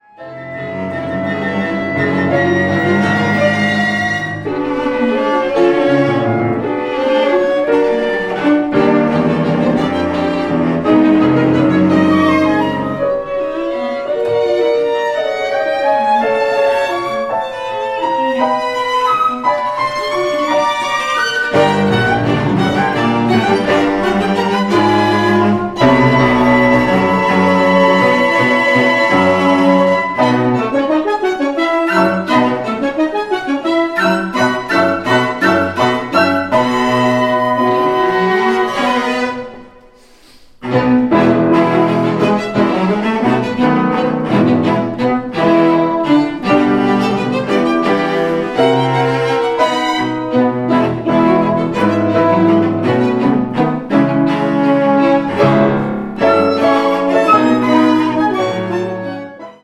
Kammerkonzert bei Steingraeber, 30.
HANS IM GLÜCK für Klavierseptett
Flöte
Horn
Klarinette
Geige
Bratsche
Cello
Klavier